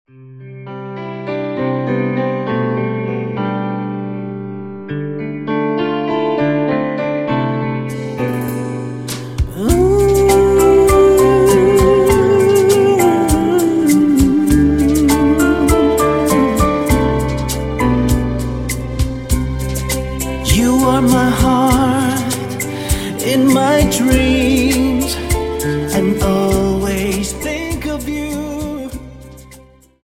Rumba 25 Song